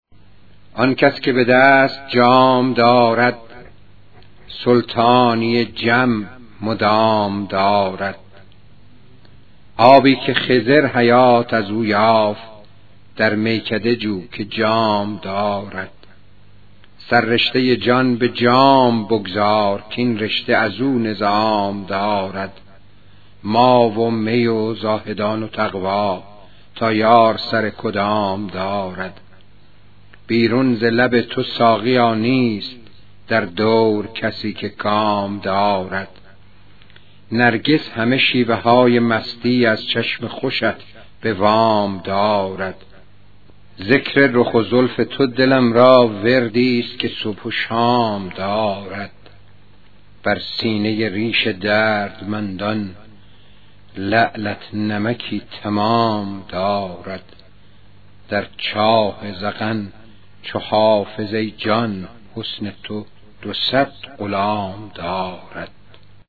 به همین بهانه بخشی از صحبت‌های سیدعلی موسوی گرمارودی در این مراسم با موضوع حافظ را می‌خوانیم: